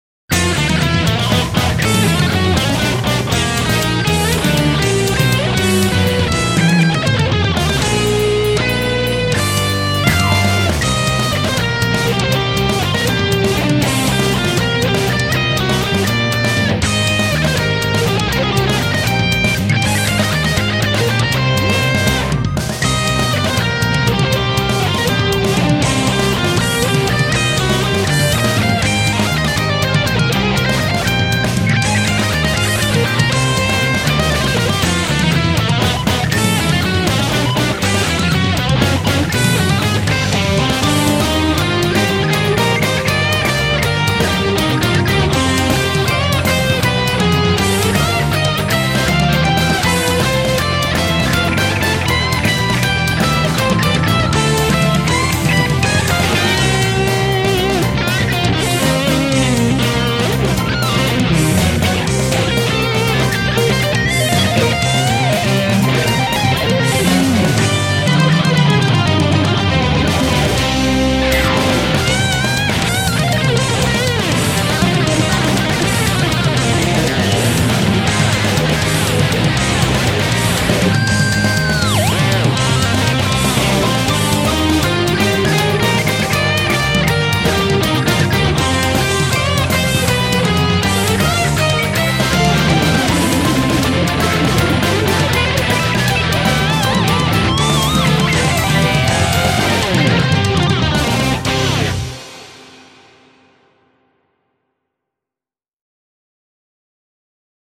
이분 컨셉이 퓨젼 곡을 쓰시면서도 곡의 기타가 은근 속주인 경우도 있습니다.